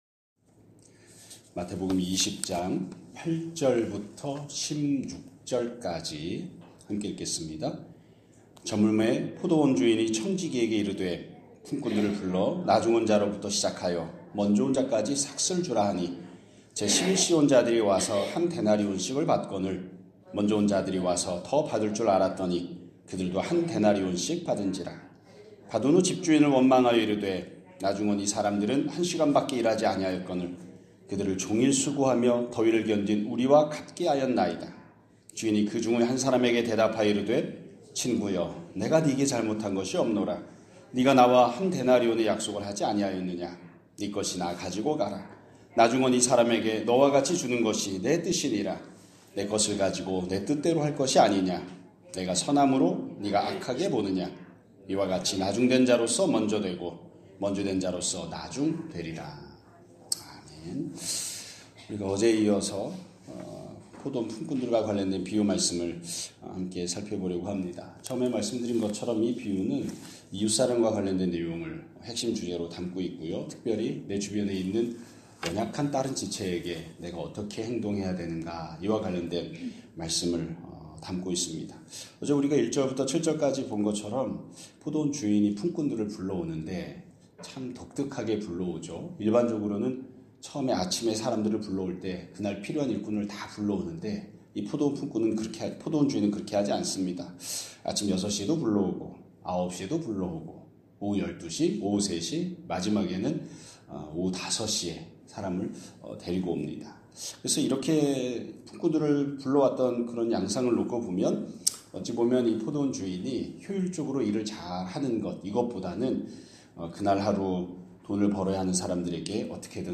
2026년 1월 14일 (수요일) <아침예배> 설교입니다.